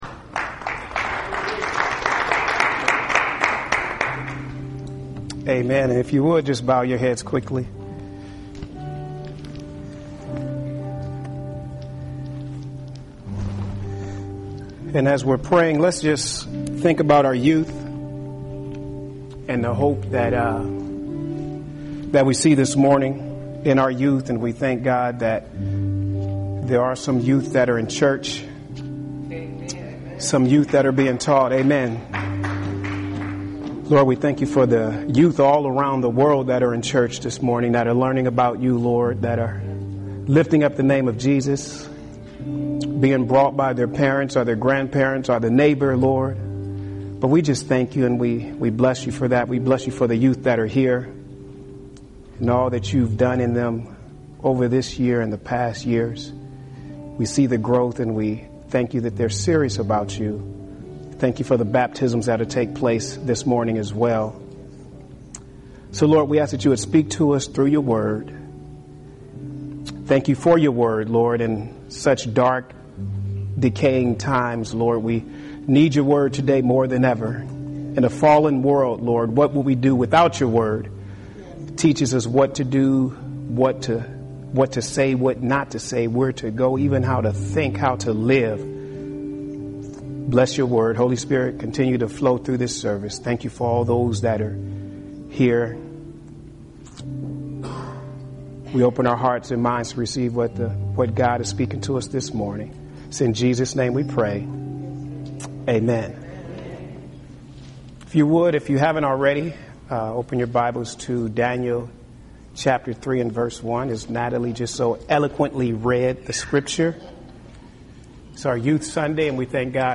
Home › Sermons › Youth Sunday – Daniel – Power, Influence, Deception of Music